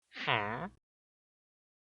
Minecraft Villager
Ambient
yt_IsuGsL80JXk_minecraft_villager.mp3